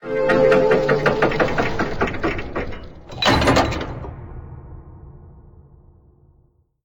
BigDoorOpen2.ogg